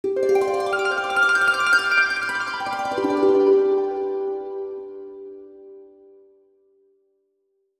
Magical Harp (11).wav